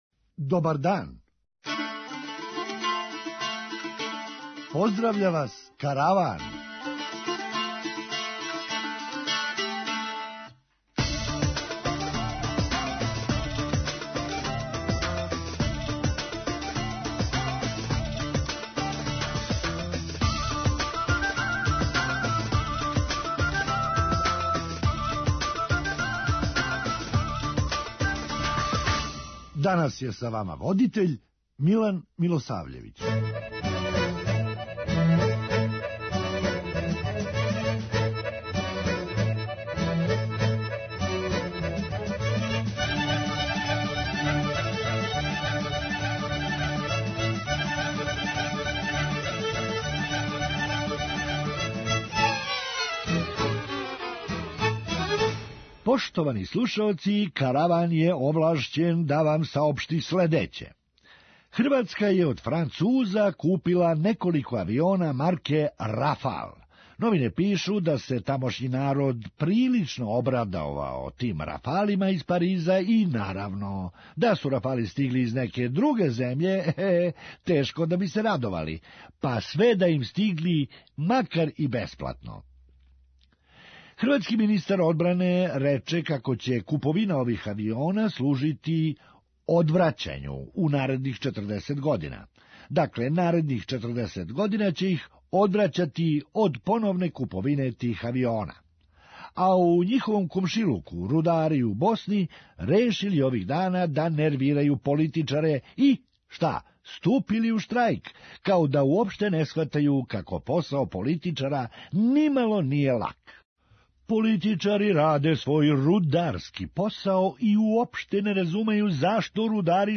Хумористичка емисија
Ма, ако је до пара, довољно ће бити да се рентирају само скије. преузми : 9.02 MB Караван Autor: Забавна редакција Радио Бeограда 1 Караван се креће ка својој дестинацији већ више од 50 година, увек добро натоварен актуелним хумором и изворним народним песмама.